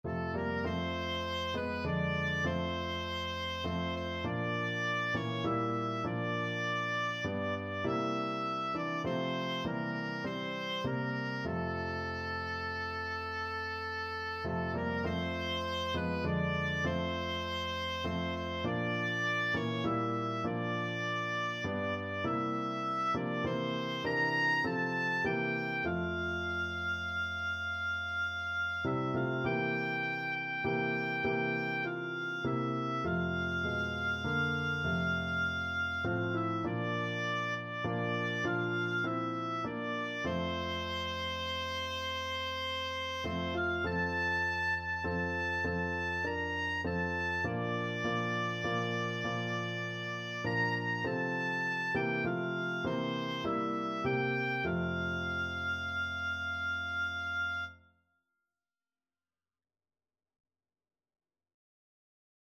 F major (Sounding Pitch) (View more F major Music for Oboe )
6/8 (View more 6/8 Music)
Classical (View more Classical Oboe Music)